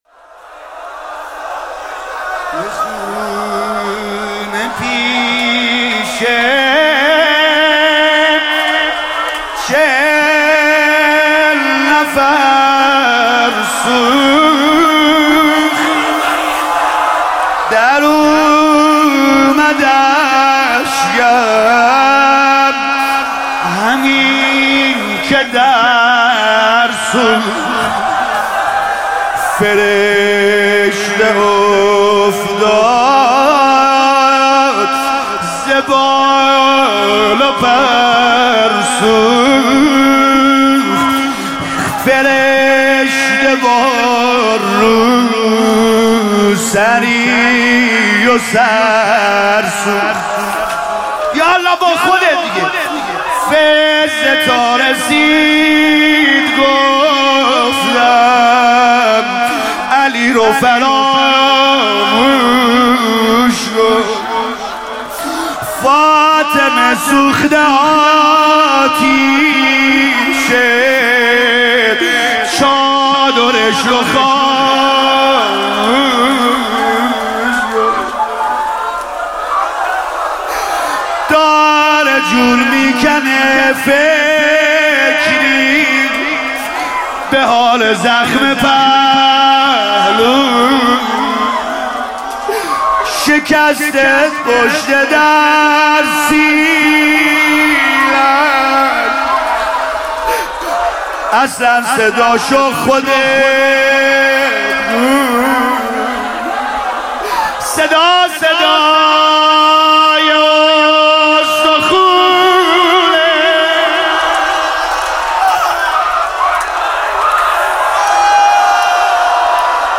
مداحی و نوحه
روضه خوانی فاطمیه